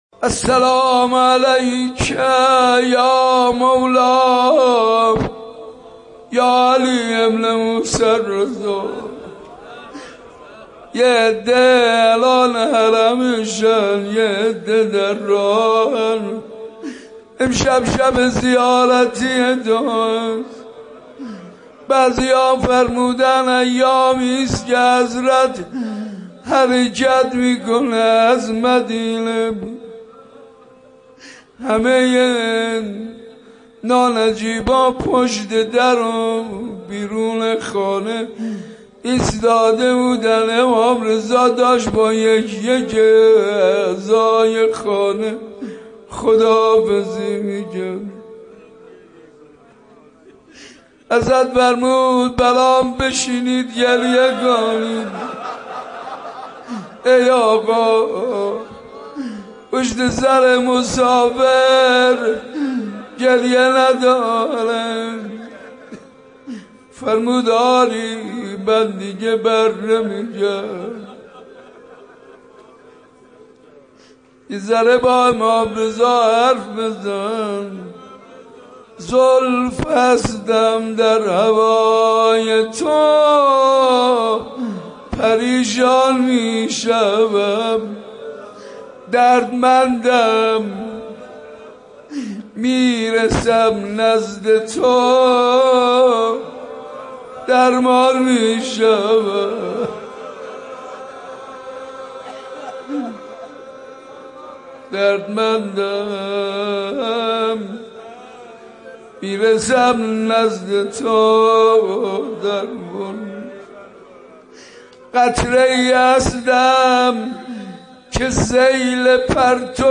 روضه و ذکرِ توسل